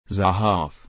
The name Zahave (/zaˈhaːf/
zahave-pronunciation.mp3